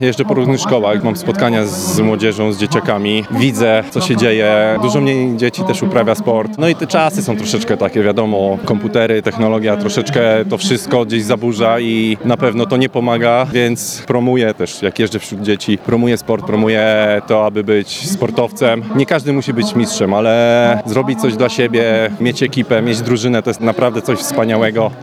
– Niestety to coraz poważniejszy problem i warto z nim walczyć chociażby przez sport – mówi ambasador akcji, puławski olimpijczyk Konrad Czerniak.